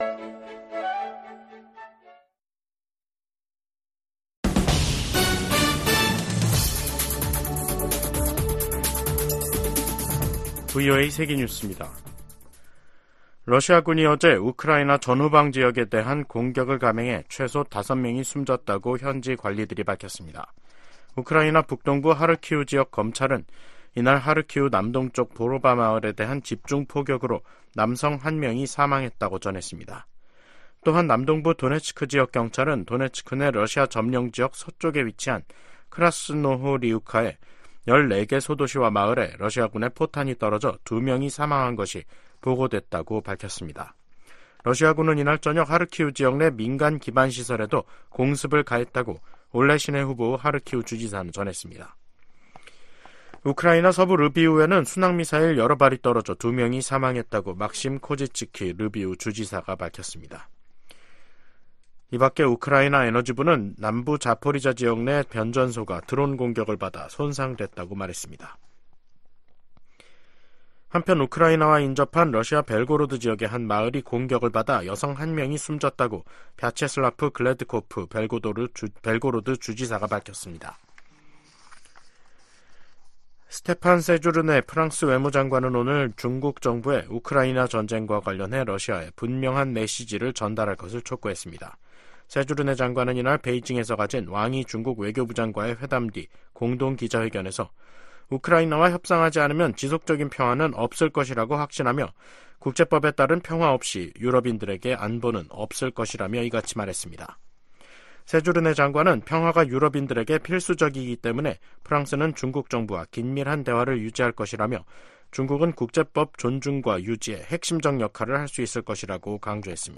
VOA 한국어 간판 뉴스 프로그램 '뉴스 투데이', 2024년 4월 1일 2부 방송입니다. 미국은 유엔 안보리 대북제재위 전문가패널의 활동 종료가 서방의 책임이라는 러시아 주장을 일축하고, 이는 북한의 불법 무기 프로그램을 막기 위한 것이라고 강조했습니다. 미국과 한국, 일본이 북한의 사이버 위협에 대응하기 위한 실무그룹 회의를 열고 지속적인 협력 방침을 확인했습니다.